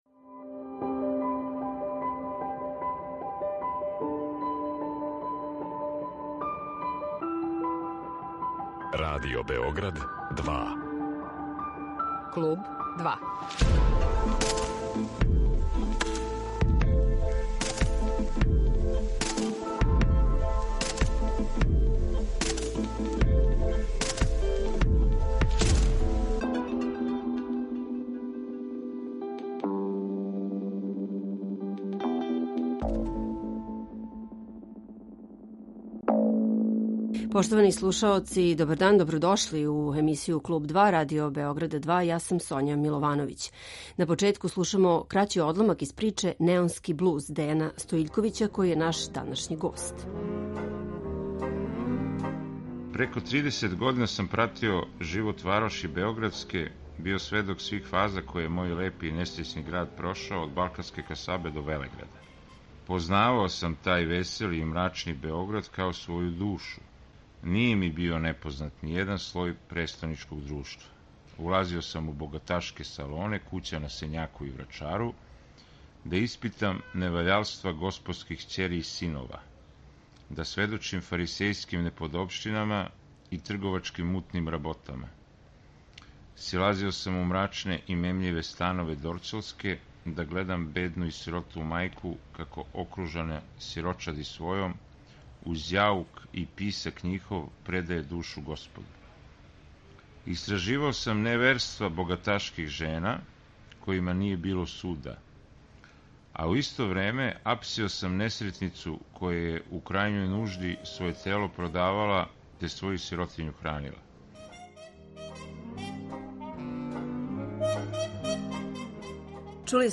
Гост Клуба 2 је писац Дејан Стојиљковић.